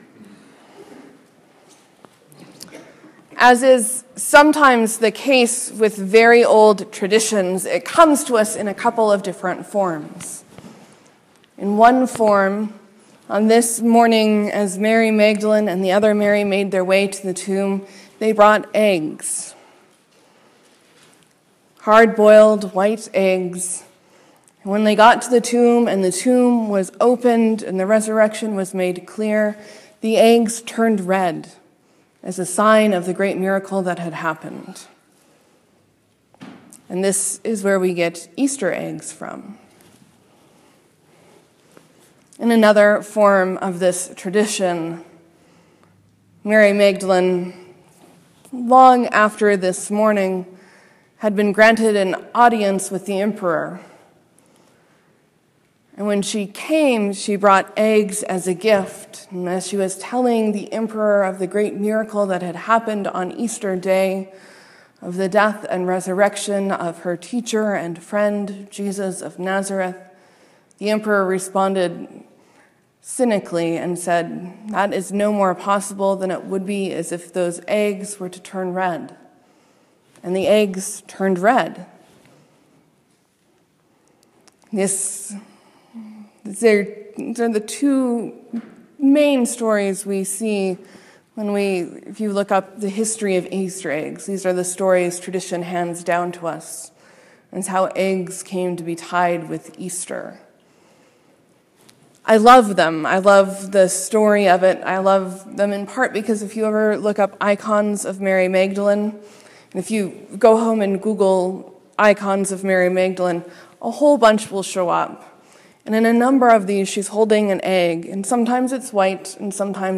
Sermon: Christ is Risen! Alleluia! Alleluia!